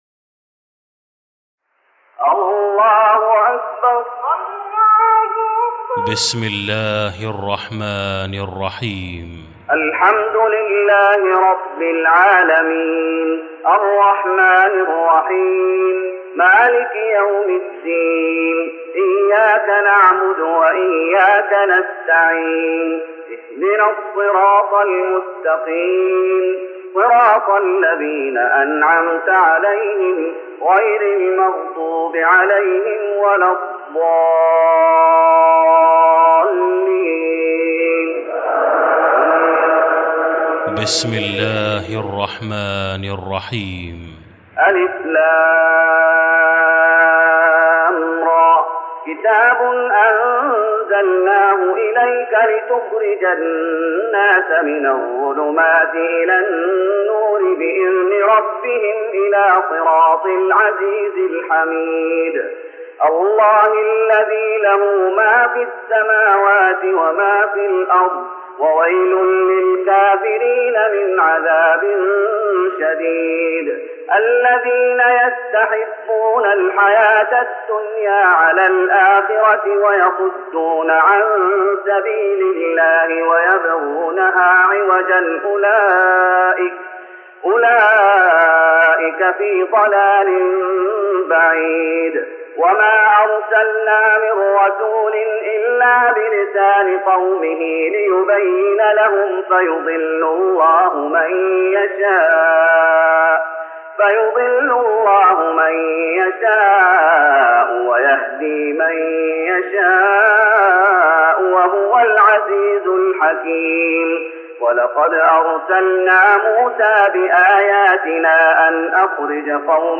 تراويح رمضان 1414هـ سورة إبراهيم Taraweeh Ramadan 1414H from Surah Ibrahim > تراويح الشيخ محمد أيوب بالنبوي 1414 🕌 > التراويح - تلاوات الحرمين